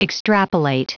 Prononciation du mot extrapolate en anglais (fichier audio)
Prononciation du mot : extrapolate